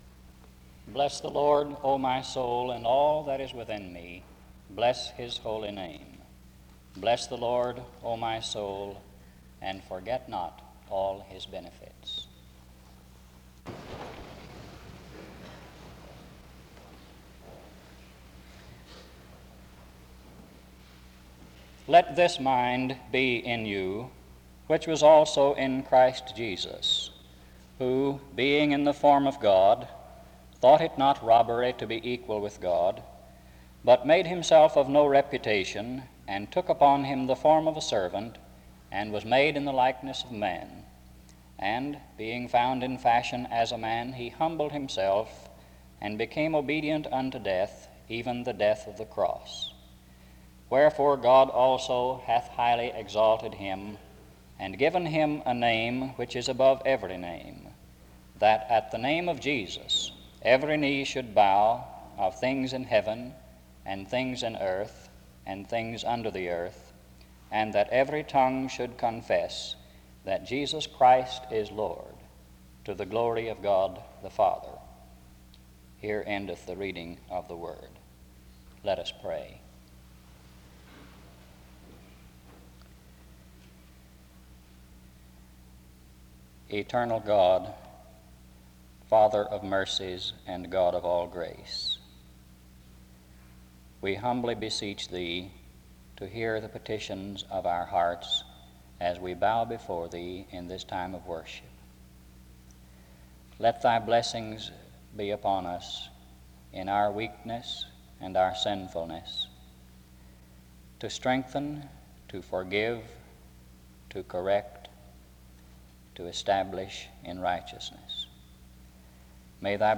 After the reading of Psalm 103:1-2 and Philippians 2:5-11, a prayer, and an introduction (start-3:55)
Location Wake Forest (N.C.)
SEBTS Chapel and Special Event Recordings SEBTS Chapel and Special Event Recordings